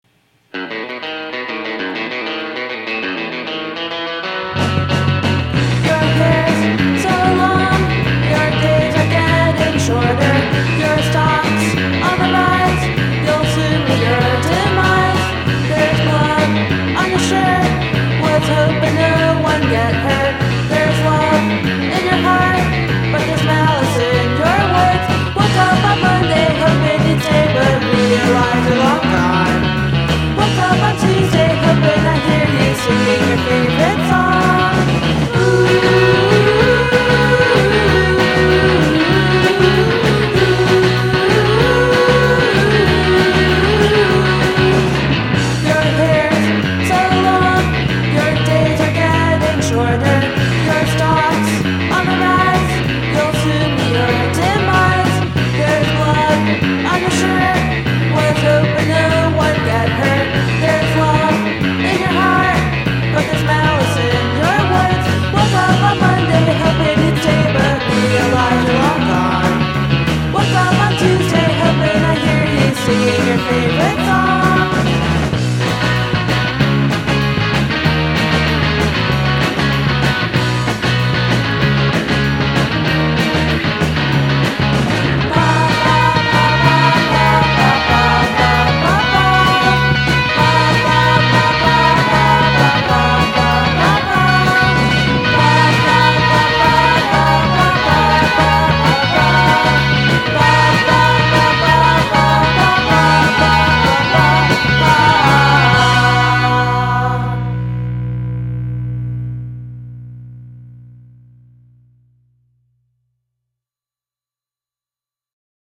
Anche qui una voce femminile
ANORAK CRUST POP